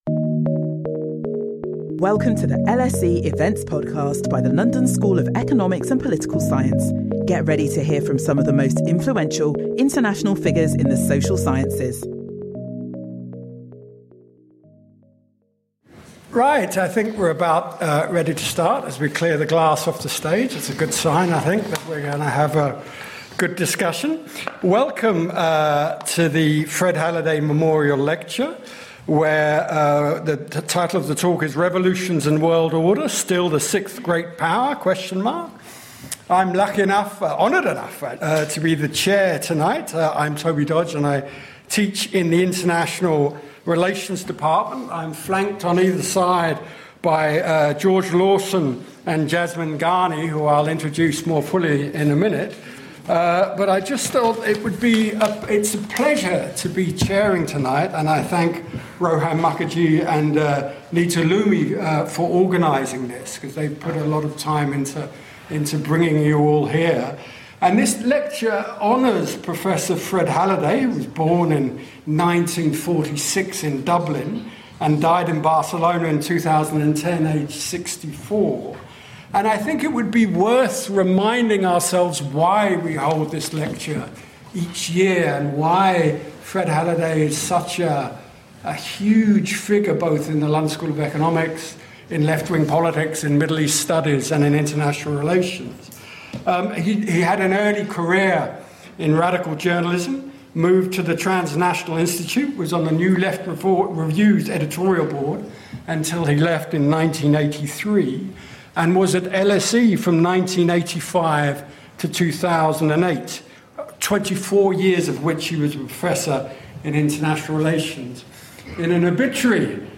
This lecture, held in honour of the renowned scholar Fred Halliday, will explore the relationship between revolutions and world order in contemporary geopolitics.